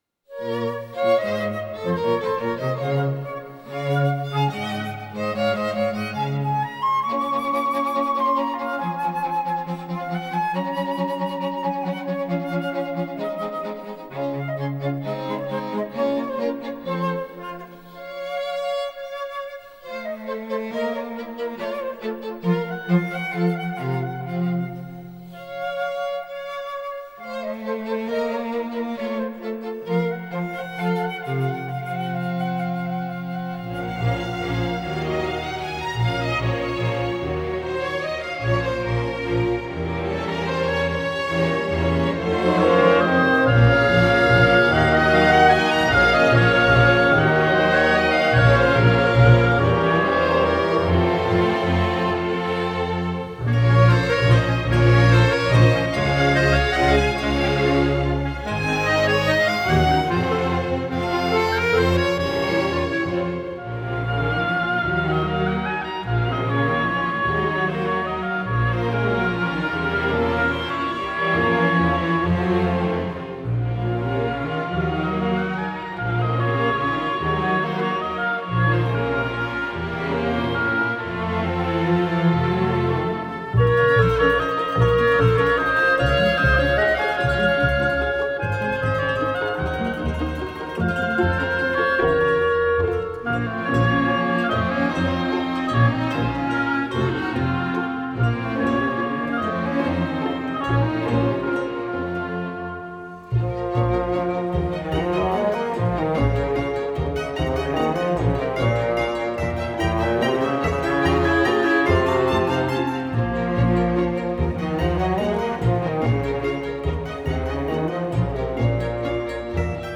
موسیقی اینسترومنتال موسیقی بیکلام